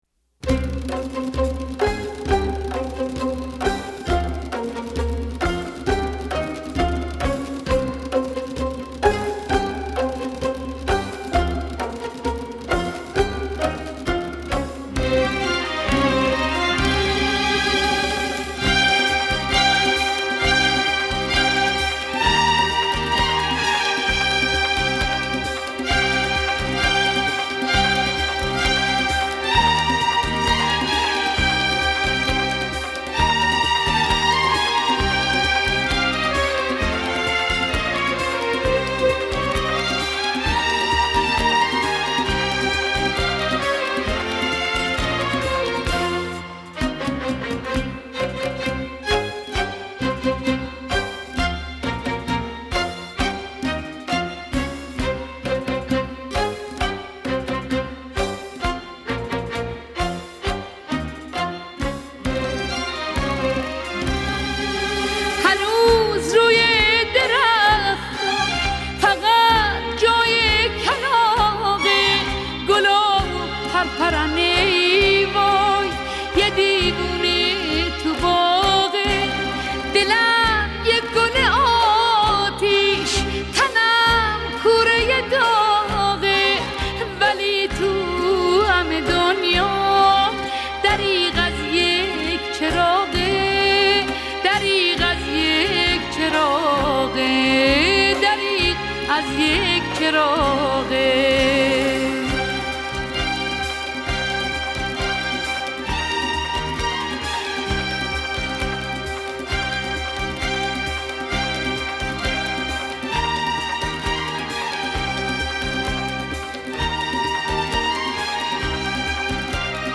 موسیقی اصیل ایرانی